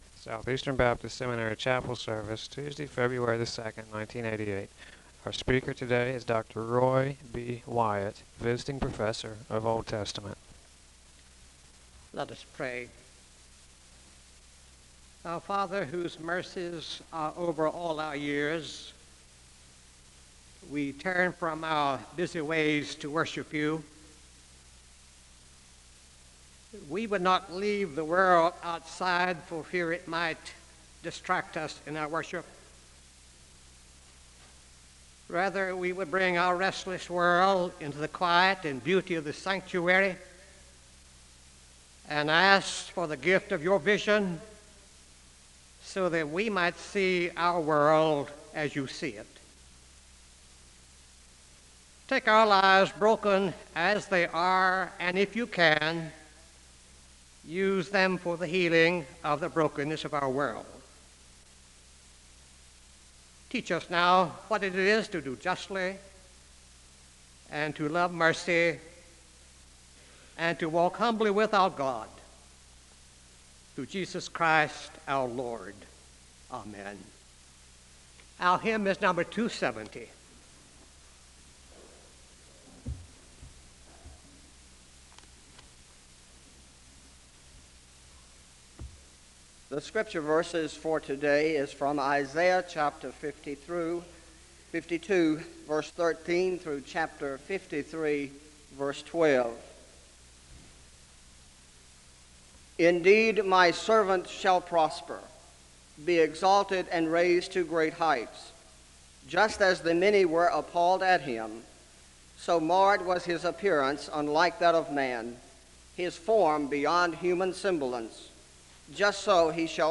The service begins with a moment of prayer (0:00-1:17). There is a Scripture reading from Isaiah (1:18-4:55).
The service concludes in a moment of prayer (21:36-22:11).
SEBTS Chapel and Special Event Recordings SEBTS Chapel and Special Event Recordings